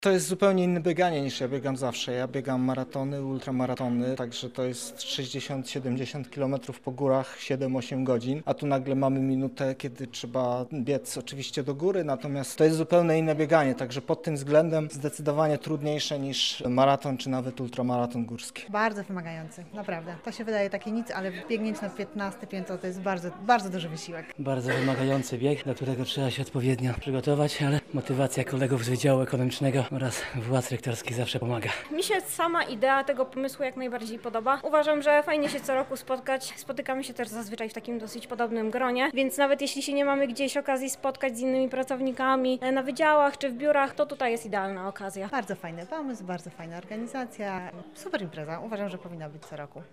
Spytaliśmy uczestników tej wyjątkowej rywalizacji o ich ocenę trudności biegu, a także samego wydarzenia.